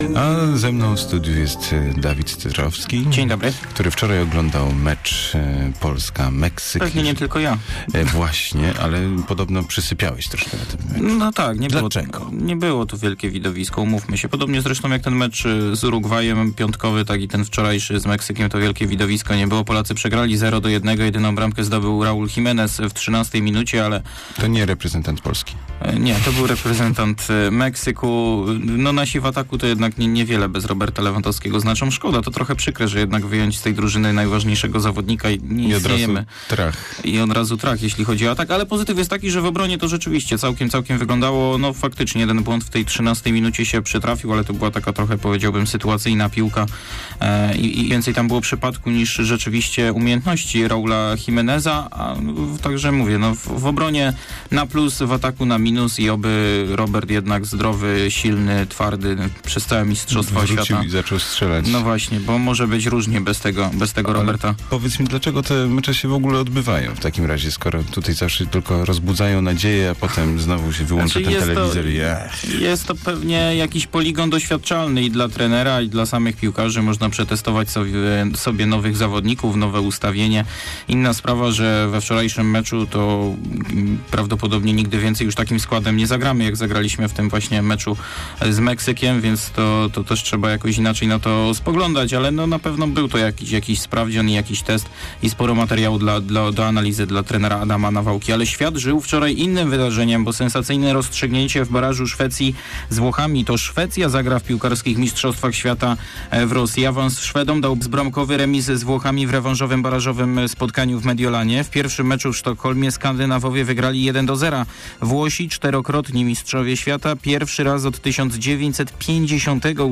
14.11 serwis sportowy godz. 7:45